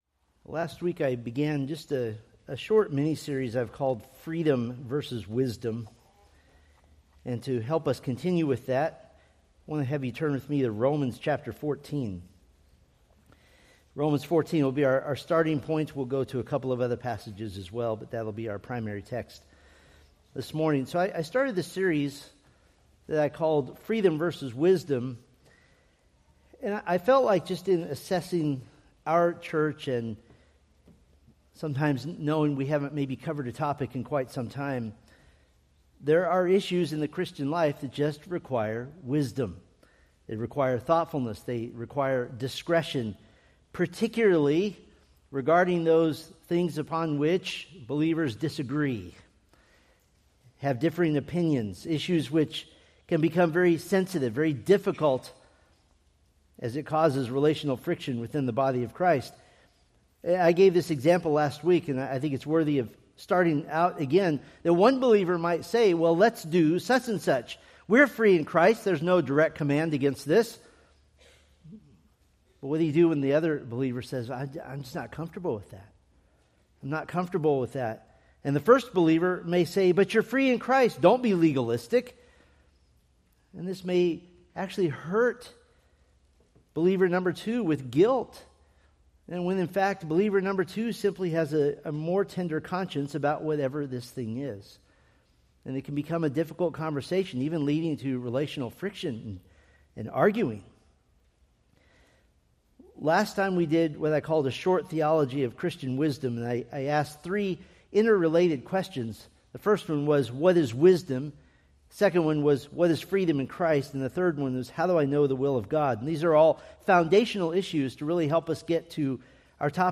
Preached September 14, 2025 from Selected Scriptures